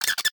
File:DTLProto4-SFX BAT ATTACK.ogg - The Cutting Room Floor
File File history File usage Metadata DTLProto4-SFX_BAT_ATTACK.ogg  (Ogg Vorbis sound file, length 0.3 s, 631 kbps) This file is an audio rip from a(n) Nintendo DS game.
DTLProto4-SFX_BAT_ATTACK.ogg.mp3